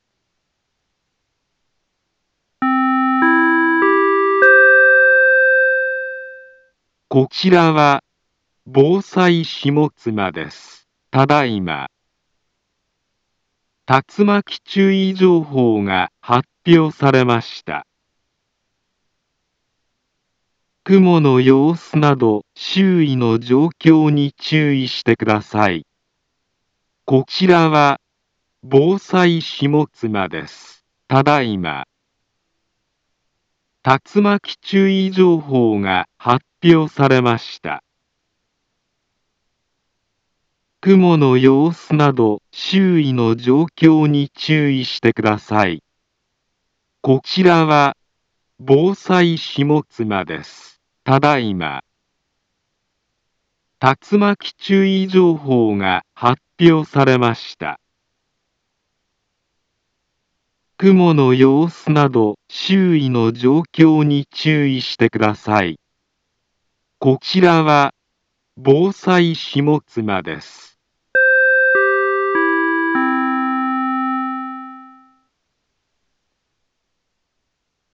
Back Home Ｊアラート情報 音声放送 再生 災害情報 カテゴリ：J-ALERT 登録日時：2024-07-24 13:34:33 インフォメーション：茨城県南部は、竜巻などの激しい突風が発生しやすい気象状況になっています。